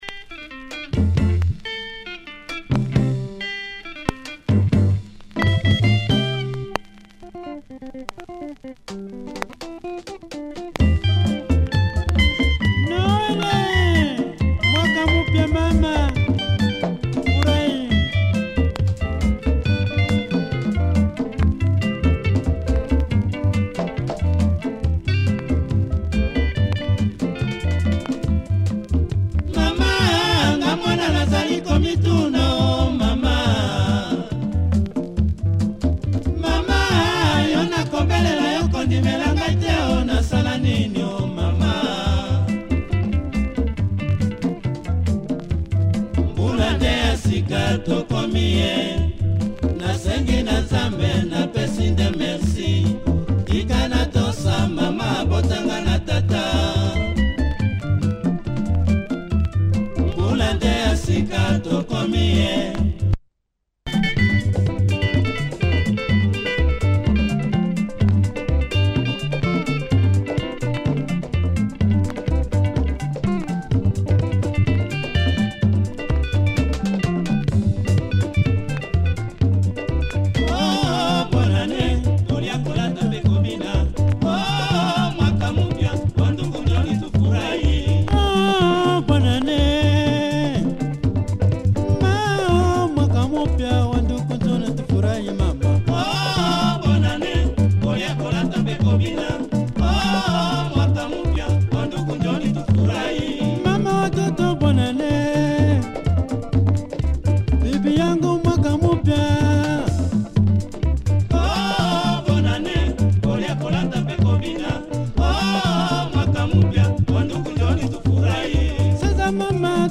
Killer club friendly groove